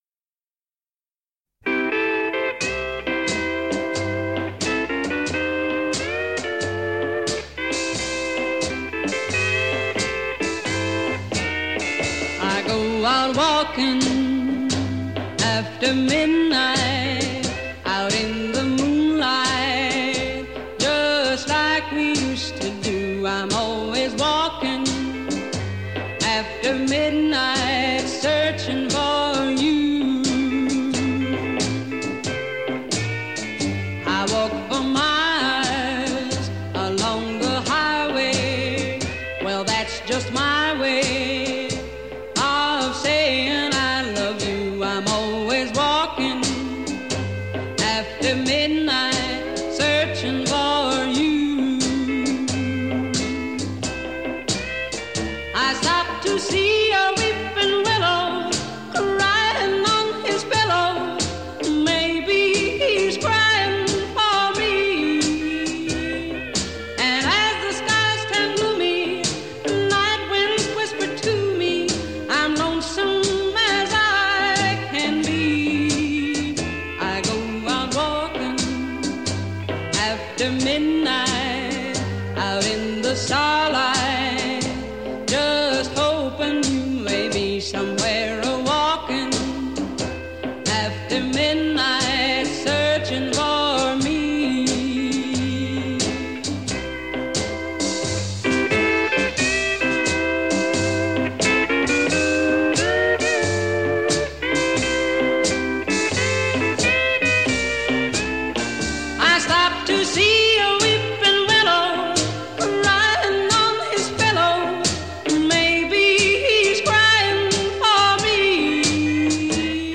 лучшие песни кантри певицы
Шагающий ритм и потустороннее звучание